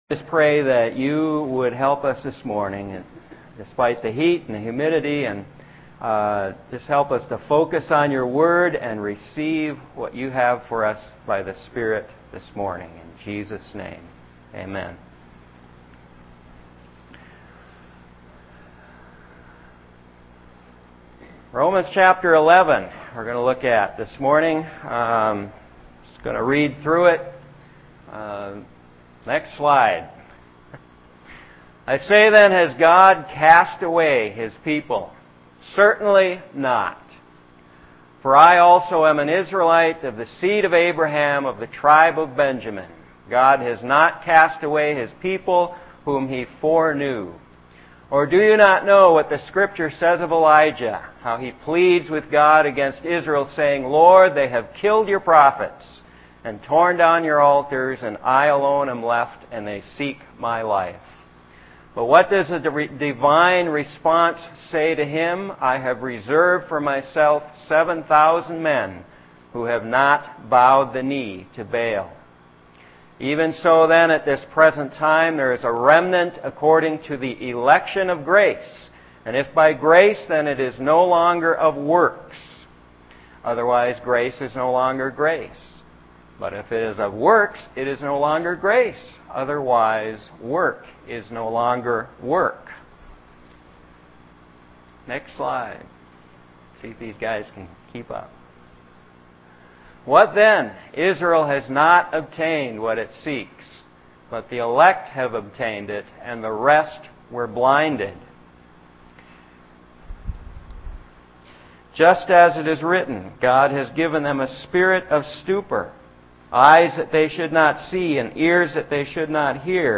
God’s mercy for all people. 120701-Rom11MercyforAll This entry was posted in sermons .